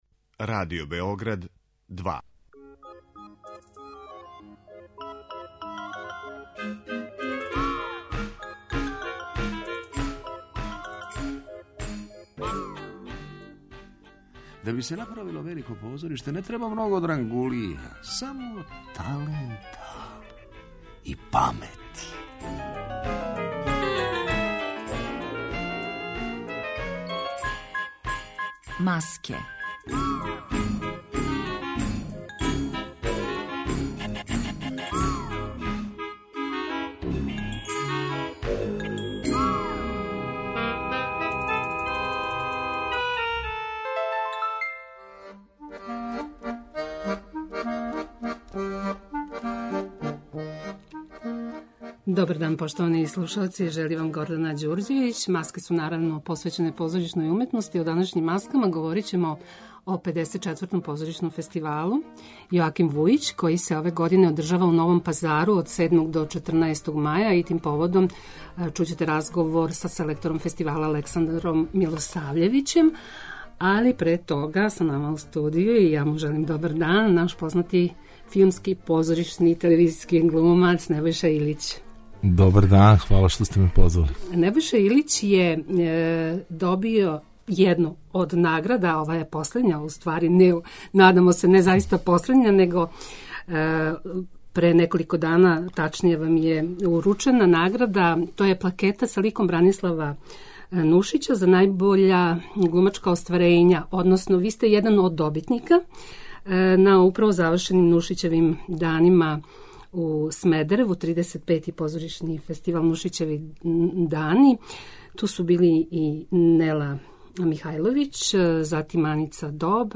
Емисија о позоришту